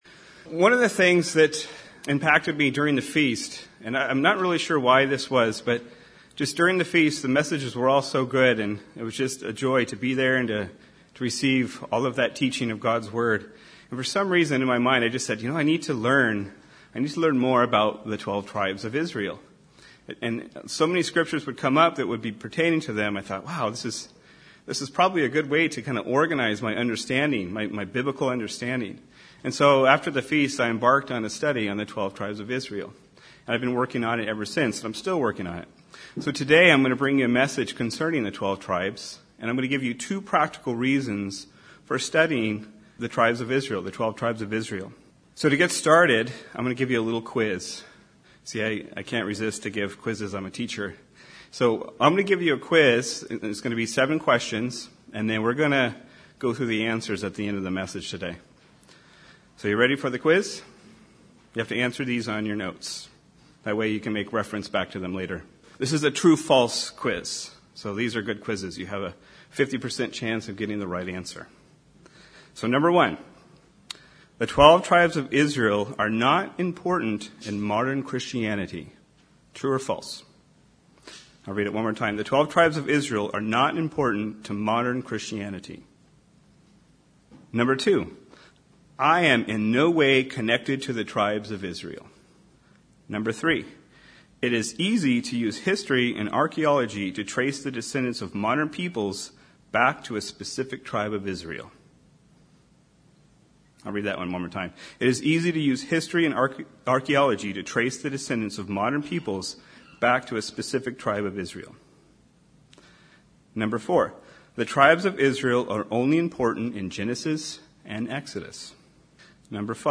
Given in Redlands, CA